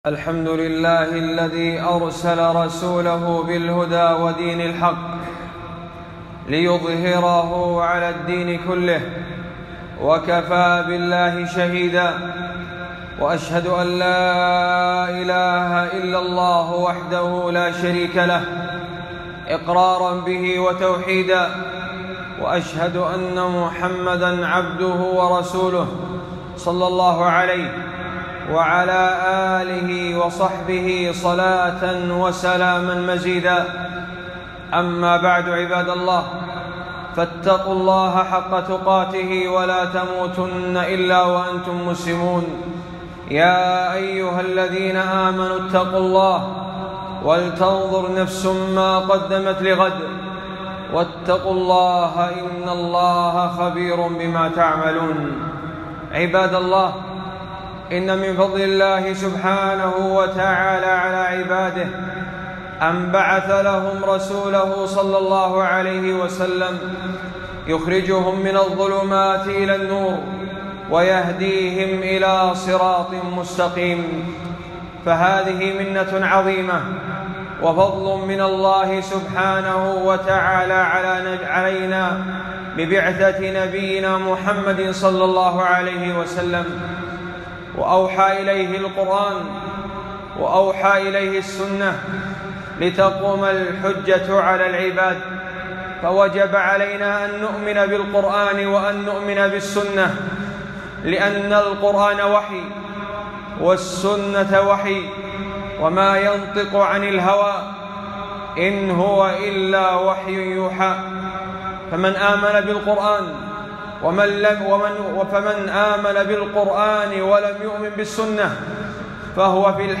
خطبة - من دلائل النبوة وصحة كتب السنة - دروس الكويت